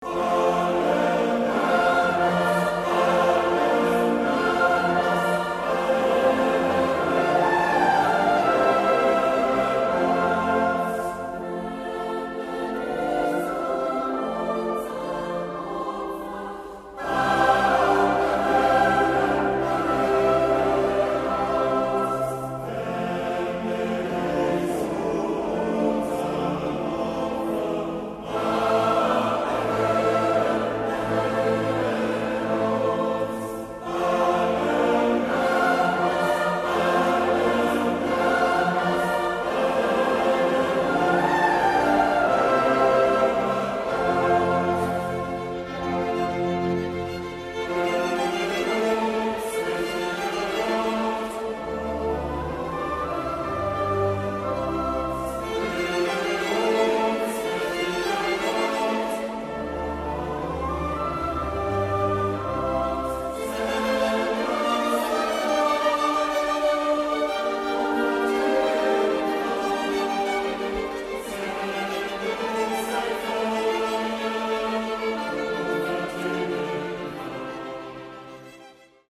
Dezember - MünchenKlang e.V. Chor und Orchester
Aufnahme vom 27.7.2019 in St. Matthäus, München